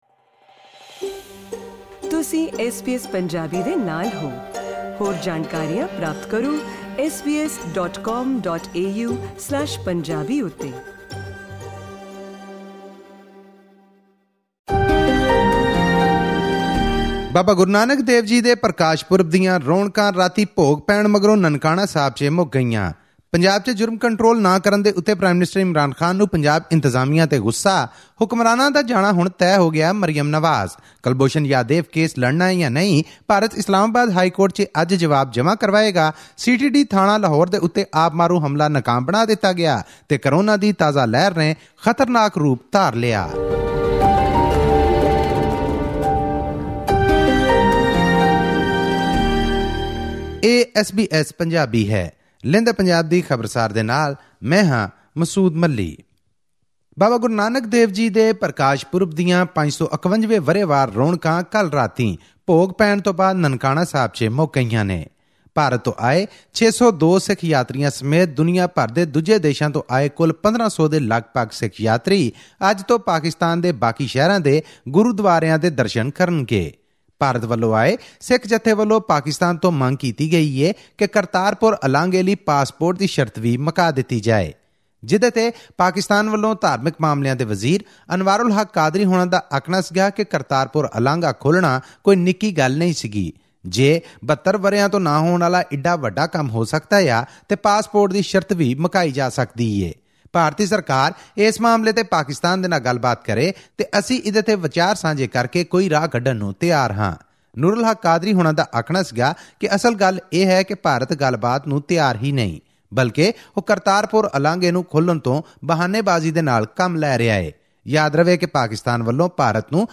Hear an excerpt of her speech at the Multan rally in this week's Lehnde Punjab di Khabarsaar.